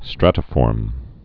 (strătə-fôrm)